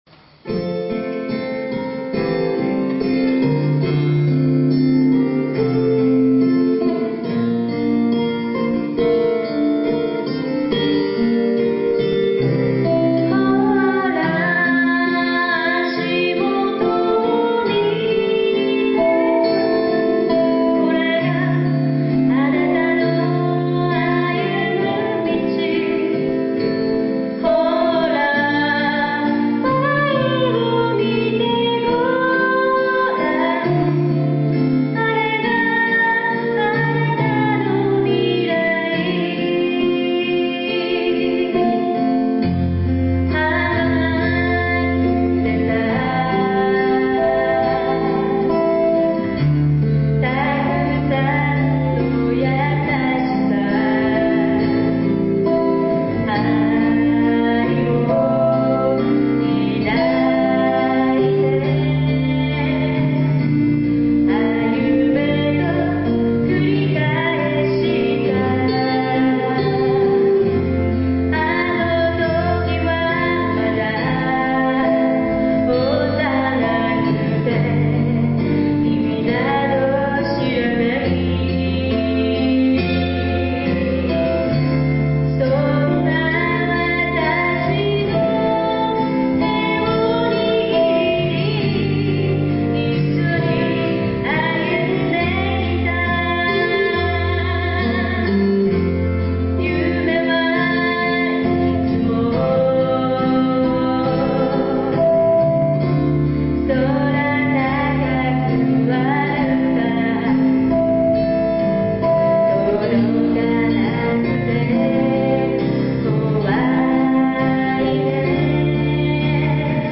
先日のコンサートで演奏した「未来へ」です。